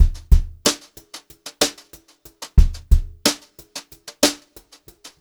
92HRBEAT1 -L.wav